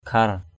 /kʱar/ (d.) tinh thể, pha lê = cristal. crystal.